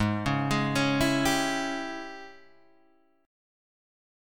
G# Major 7th Suspended 4th Sharp 5th